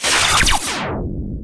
laserout.ogg